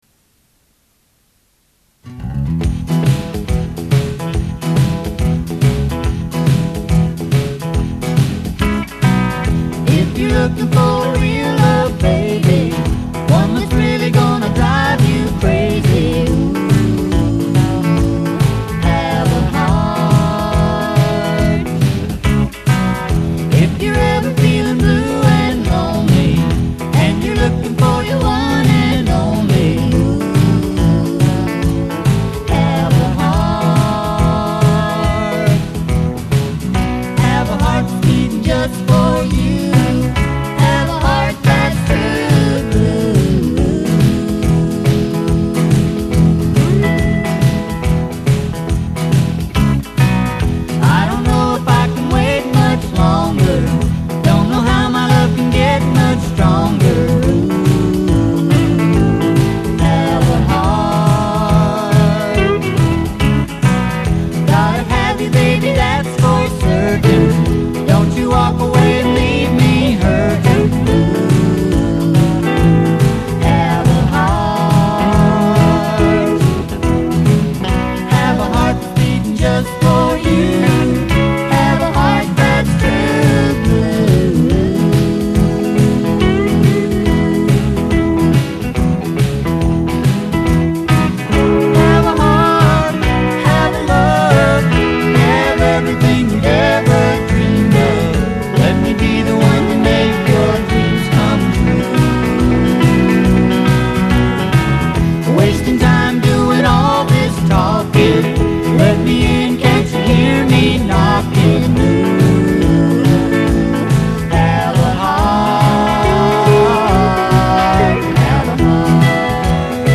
STUDIO DEMO